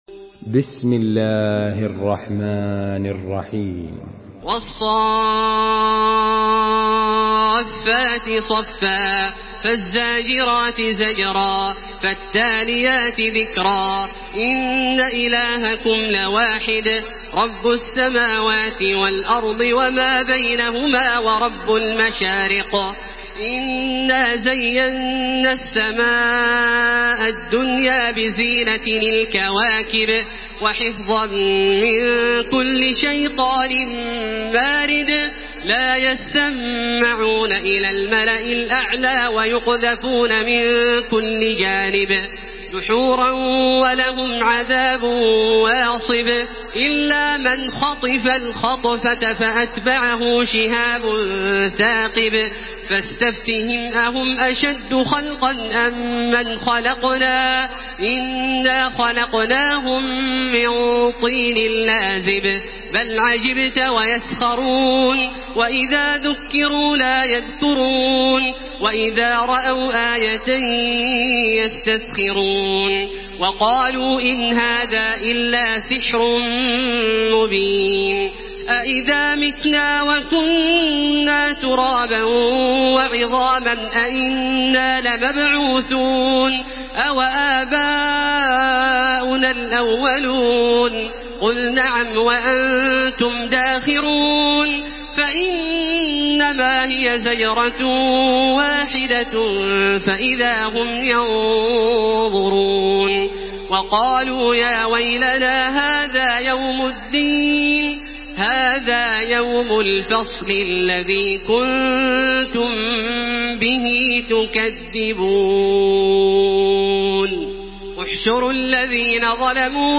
Surah As-Saffat MP3 in the Voice of Makkah Taraweeh 1433 in Hafs Narration
Listen and download the full recitation in MP3 format via direct and fast links in multiple qualities to your mobile phone.